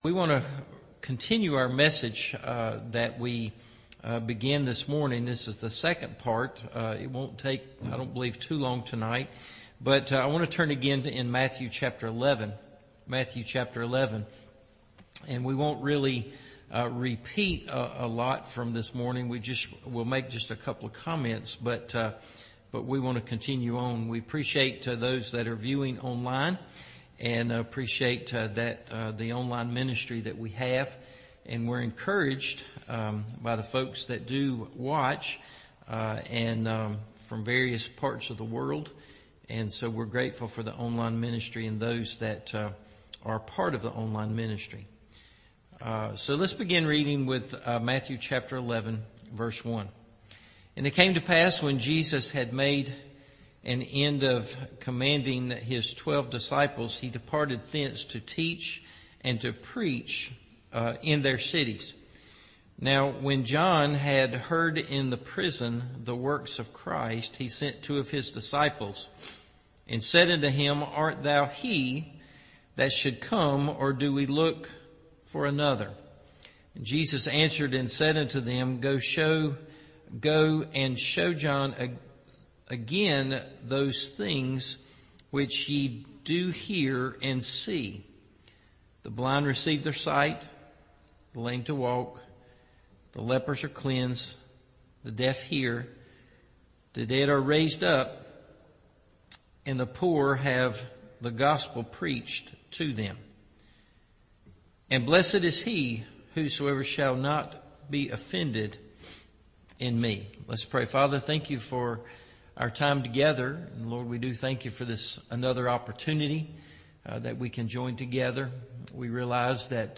“Why Doubt?” – Part 2 Evening Service